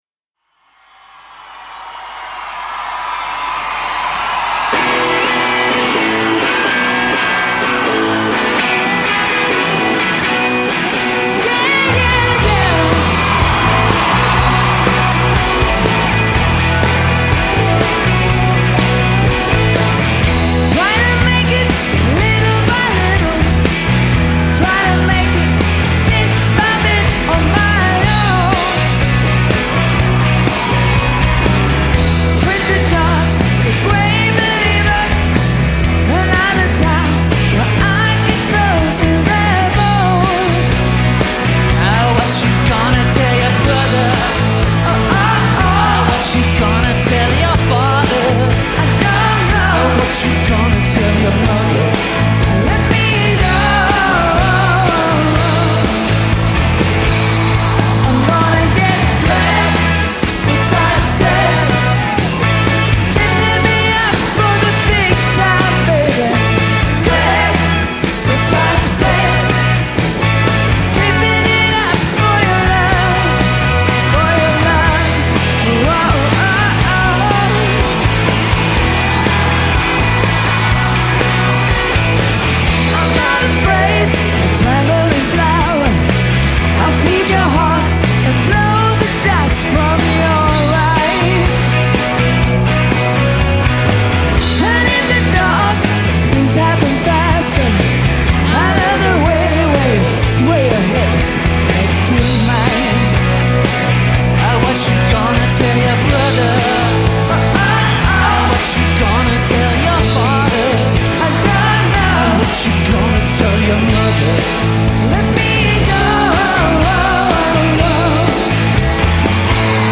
스웨덴 팝 듀오
라이브 앨범